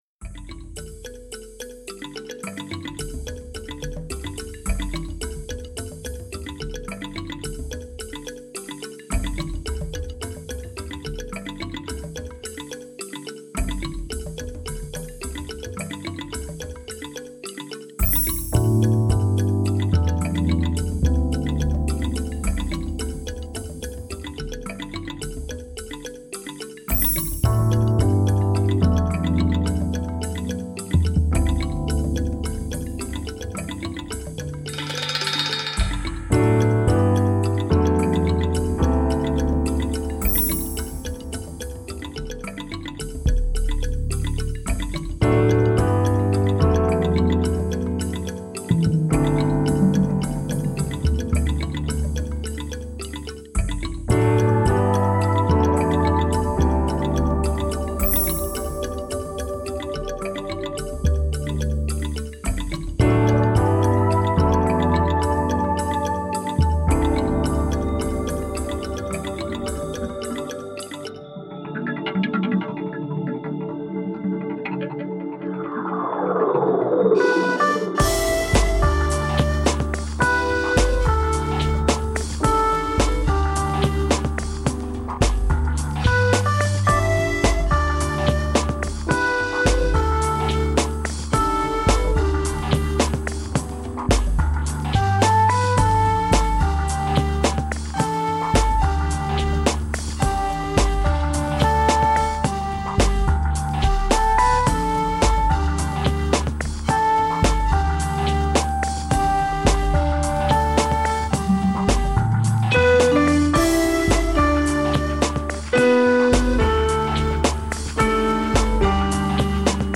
非洲风情